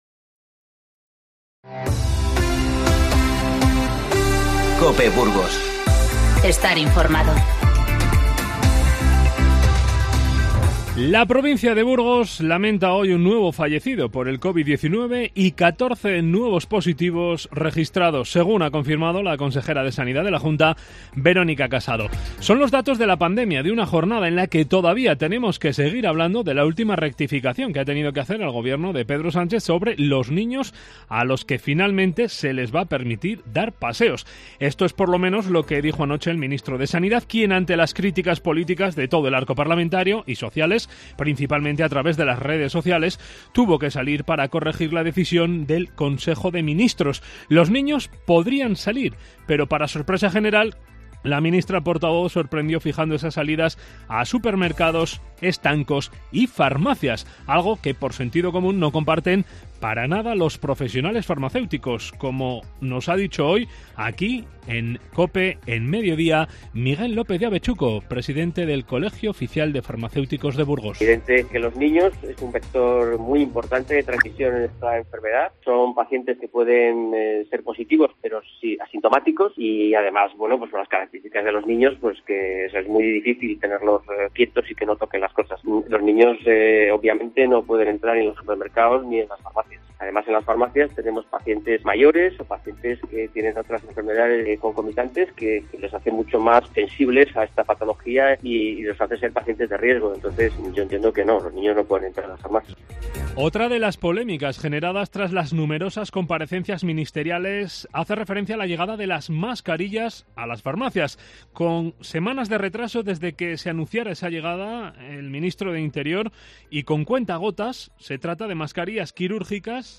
INFORMATIVO MEDIODÍA 22/4